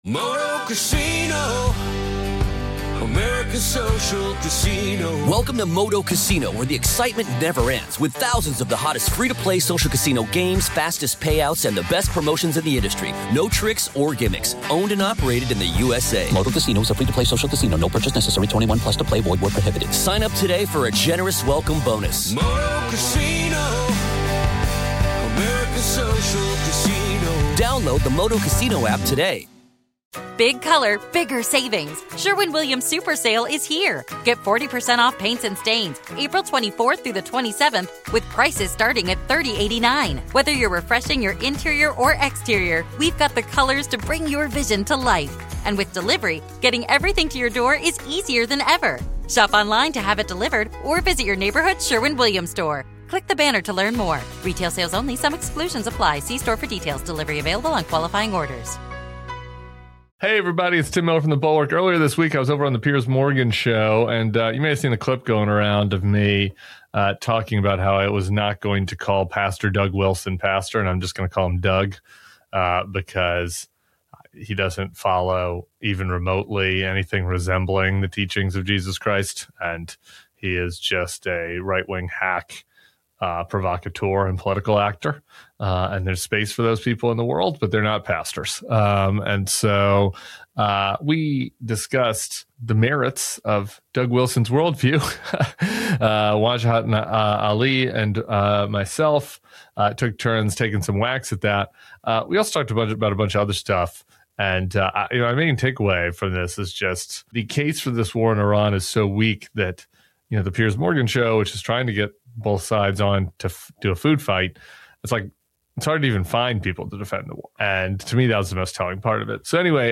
Tim Miller appeared on Piers Morgan Uncensored and got into a heated on-air clash with a right-wing “pastor” while dismantling the case for Trump’s war with Iran.